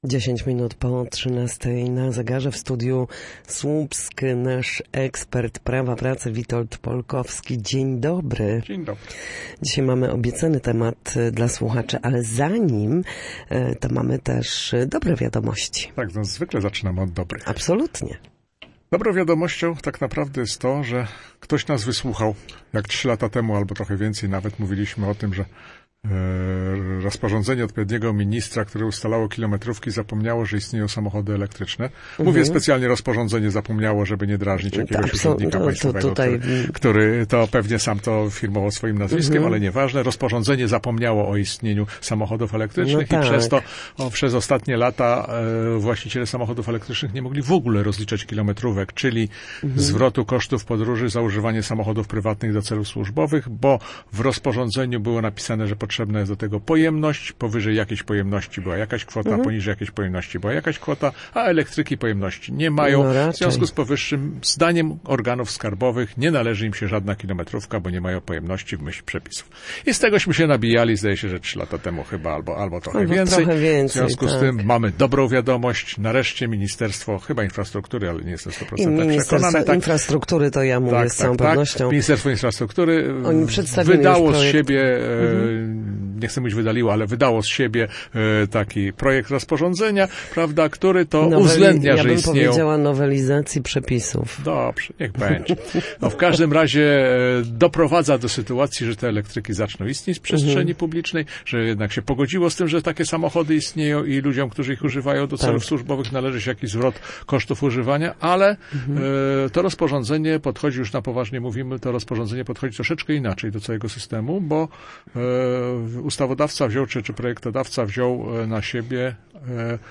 W każdy wtorek po godzinie 13:00 na antenie Studia Słupsk przybliżamy zagadnienia z zakresu prawa pracy.